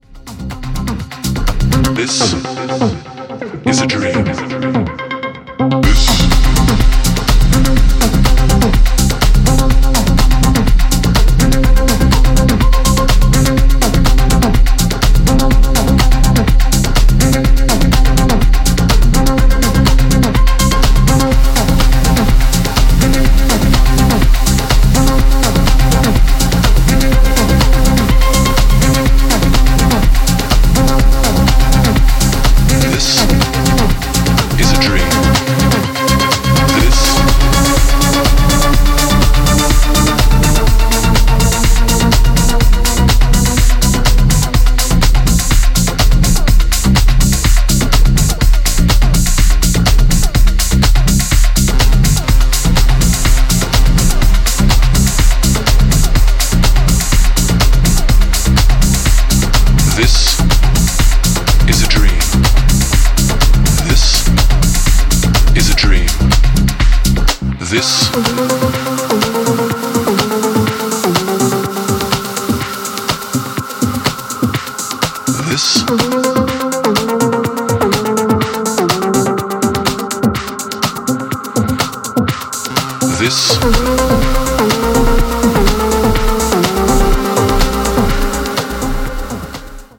The title track is a synth-heavy dancefloor banger
with hypnotic FX